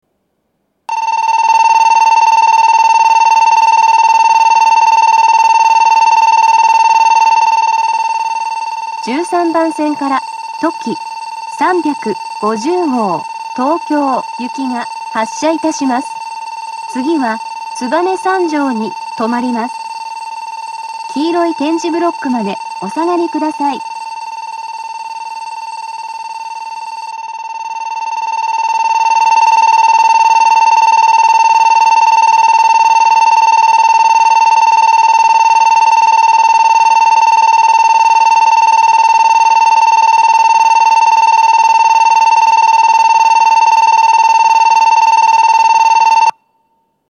２面４線のホームで、全ホームで同じ発車ベルが流れます。
２０２１年９月１２日にはCOSMOS連動の放送が更新され、HOYA製の合成音声による放送になっています。
１３番線発車ベル とき３５０号東京行の放送です。